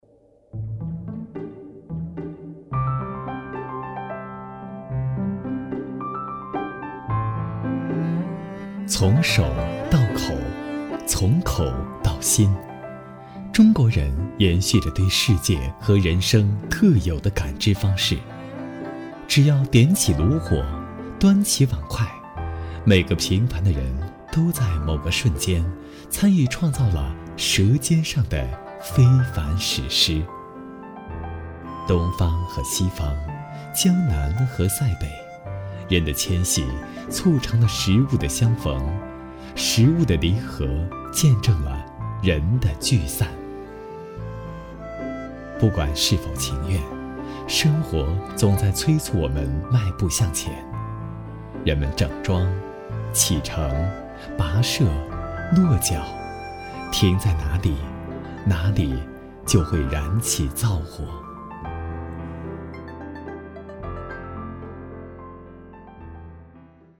配音演员自我介绍 亲切，温暖